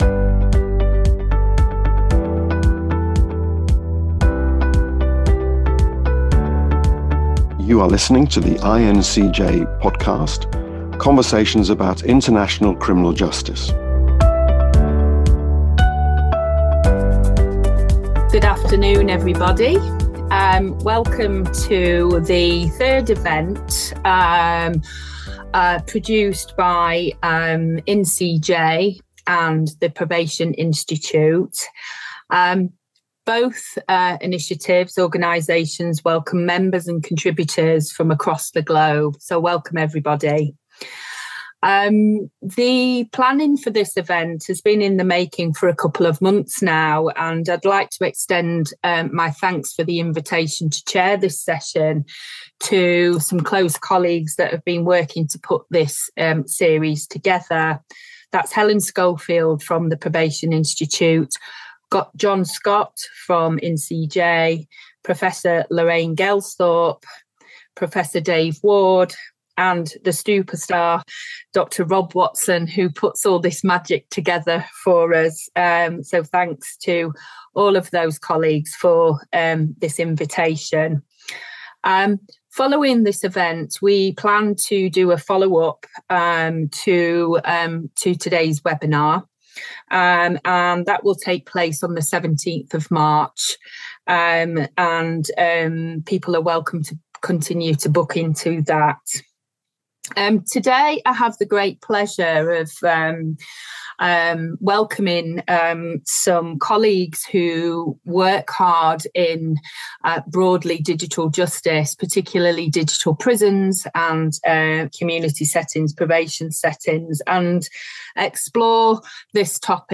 This webinar brought colleagues from IN-CJ and the Probation Institute together to examine what “digital rehabilitation” means in practice across prisons, probation, and courts. Rather than treating technology as a straightforward upgrade, the discussion repeatedly returned to the question of who benefits, who is left behind, and what kinds of institutional cultures are needed for digital tools to support rehabilitation rather than simply extend control.